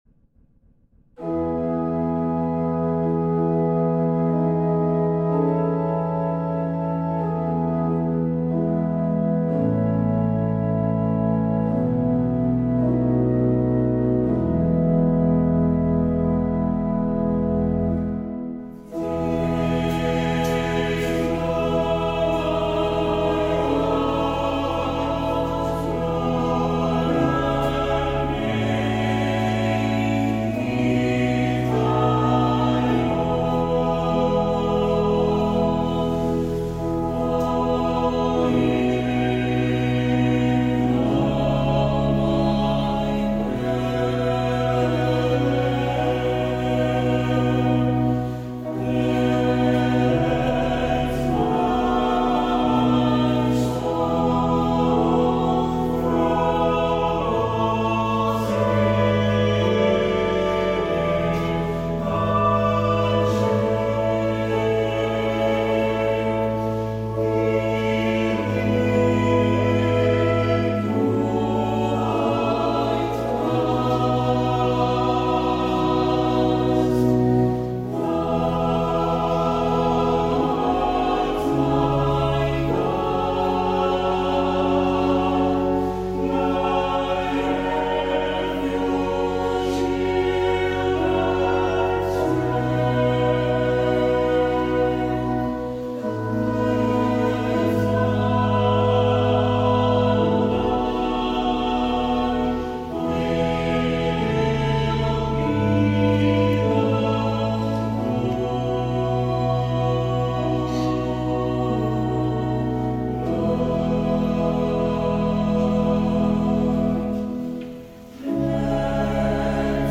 New apostolic church hymn